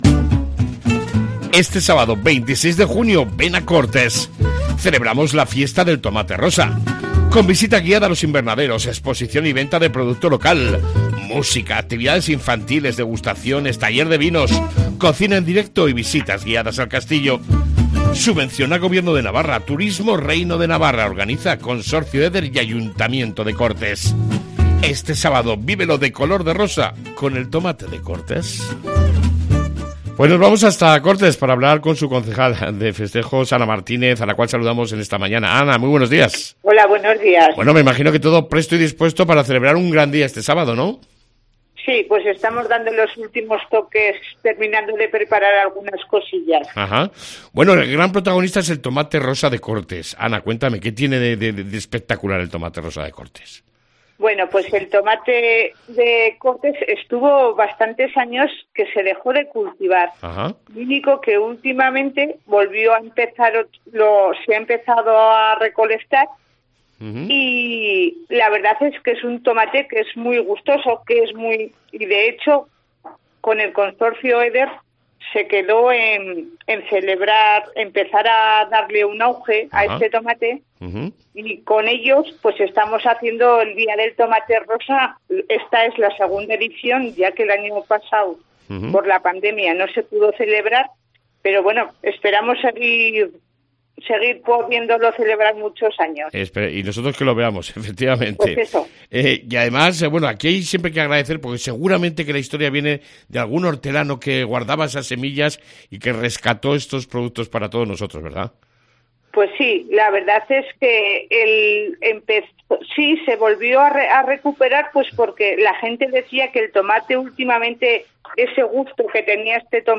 AUDIO: Hablamos con la Concejal de festejos Ana Martínez